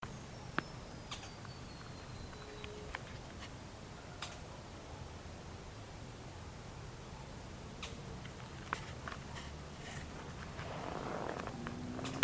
Canebrake Groundcreeper (Clibanornis dendrocolaptoides)
3 ejemplares observados en una zona de cañas. confiados pero siempre muy ocultos.
Condition: Wild
Certainty: Observed, Recorded vocal